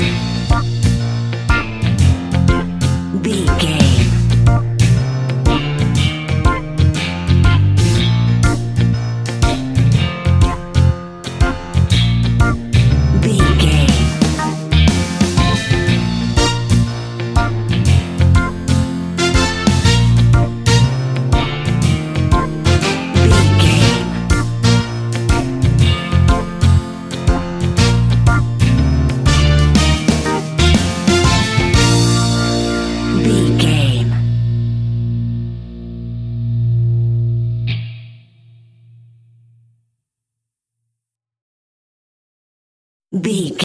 Hot summer sunshing reggae music for your next BBQ!
Ionian/Major
B♭
Slow
dub
laid back
chilled
off beat
drums
skank guitar
hammond organ
percussion
horns